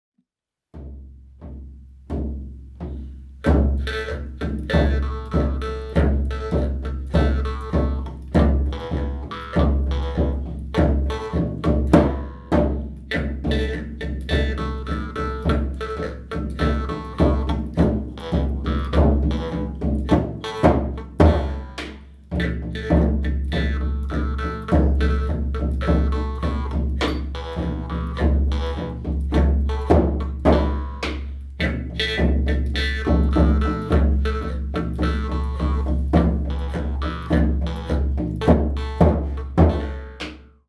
Maultrommeln
Track 3,  Typ Kufstein: Sterzinger Marsch (Trad., Neuzeit)